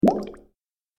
دانلود آهنگ آب 72 از افکت صوتی طبیعت و محیط
جلوه های صوتی
دانلود صدای آب 72 از ساعد نیوز با لینک مستقیم و کیفیت بالا